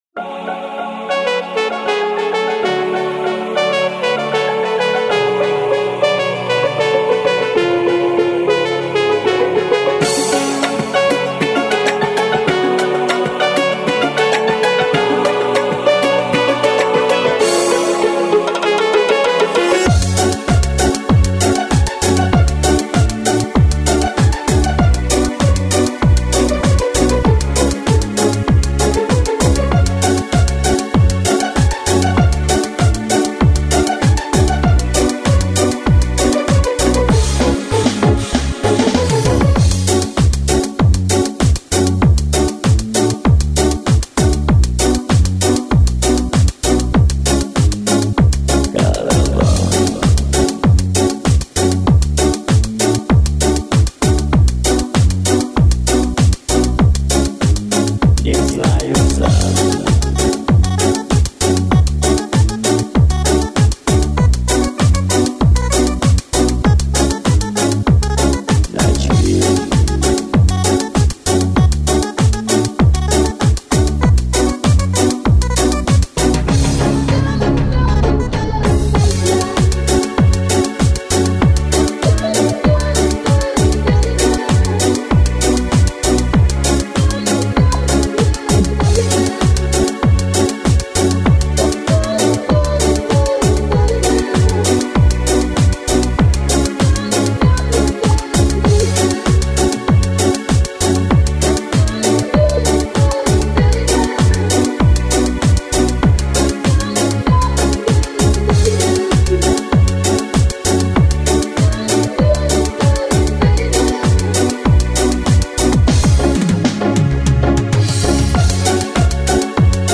Часто используемые минусовки хорошего качества